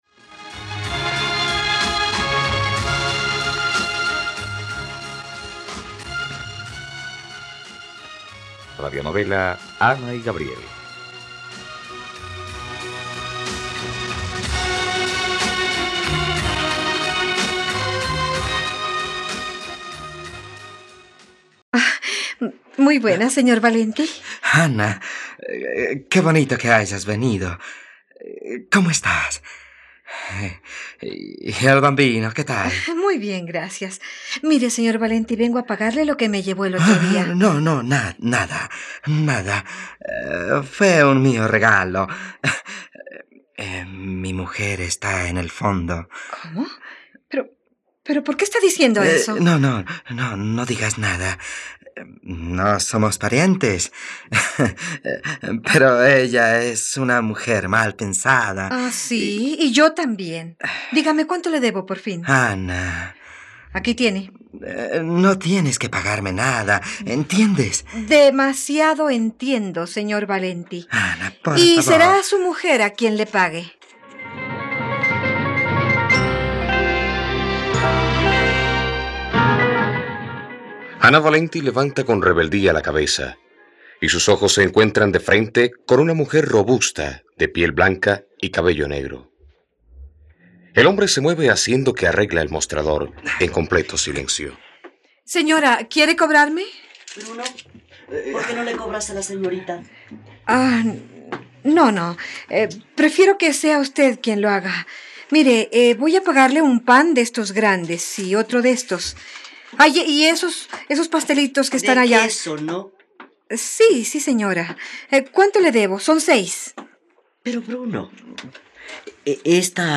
Ana y Gabriel - Radionovela, capítulo 22 | RTVCPlay